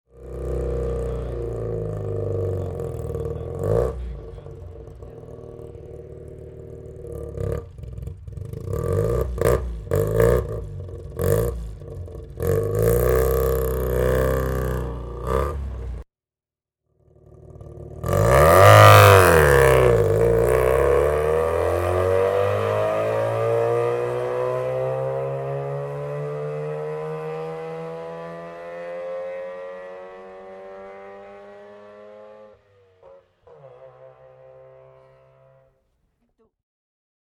Mazda Cosmo 110 S (1969) - im Stand und Start